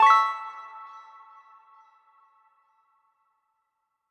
meeting-started.mp3